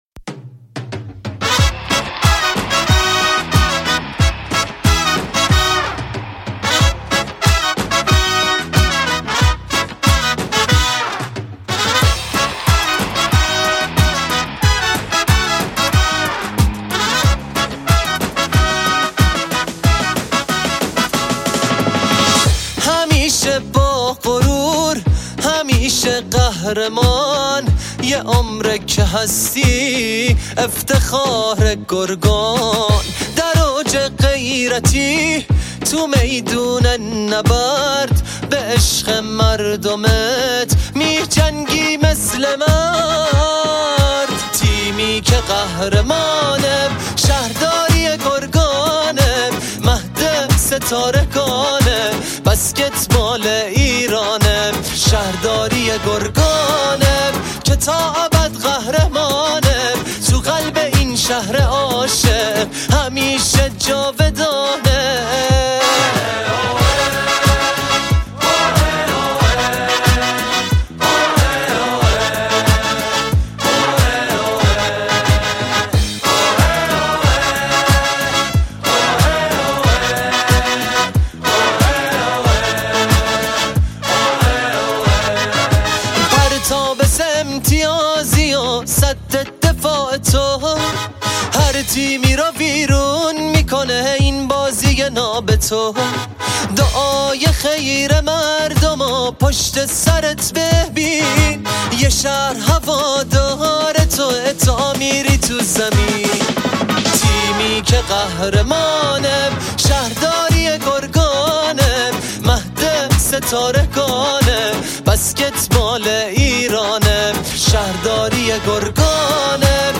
عاشقانه و غمگین